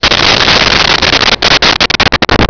Sfx Amb Scrape Metal 03
sfx_amb_scrape_metal_03.wav